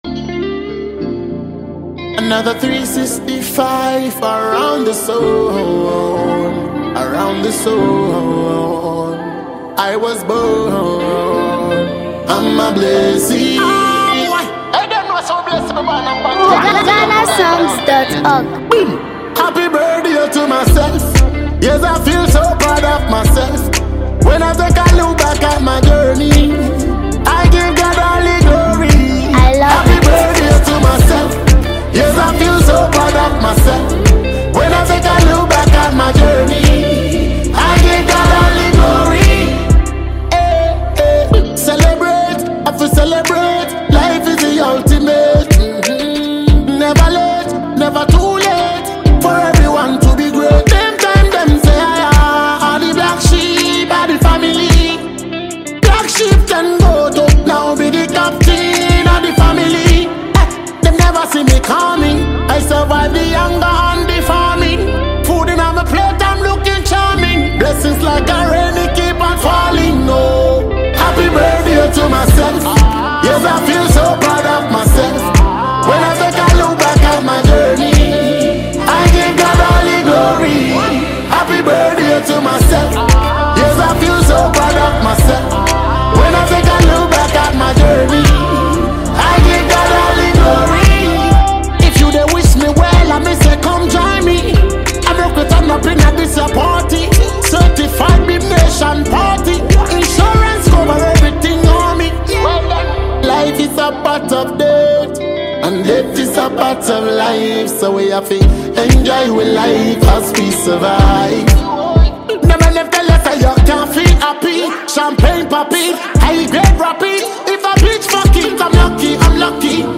• Inspiring and uplifting message
• Smooth Afrobeat and reggae vibe
Powerful vocals